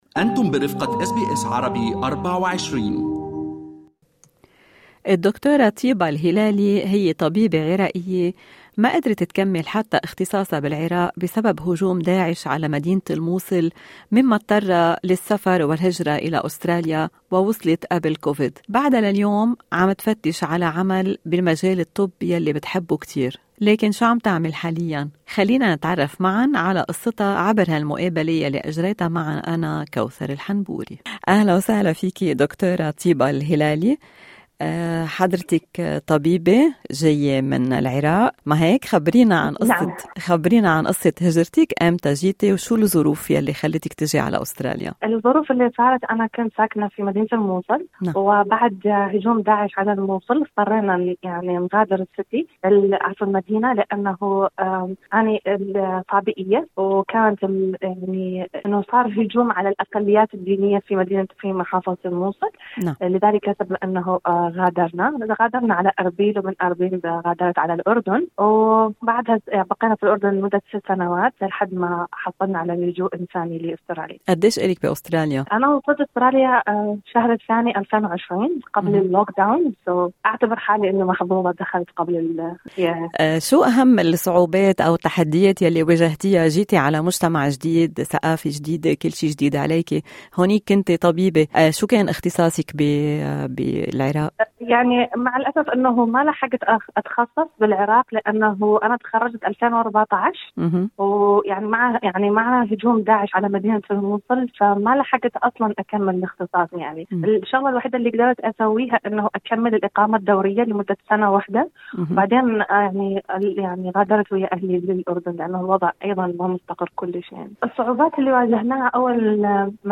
تعرفوا الى قصتها من خلال المقابلة